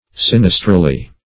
Search Result for " sinistrally" : The Collaborative International Dictionary of English v.0.48: Sinistrally \Sin"is*tral*ly\, adv. Toward the left; in a sinistral manner.